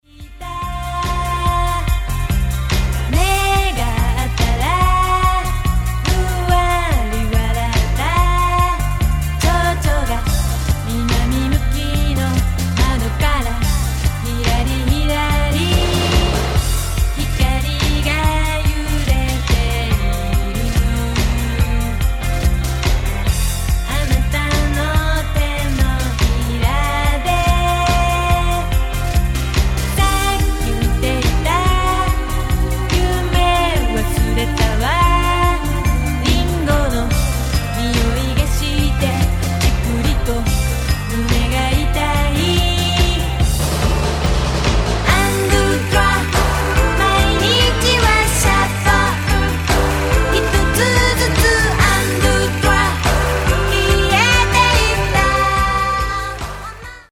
みずみずしいカラフルポップが炸裂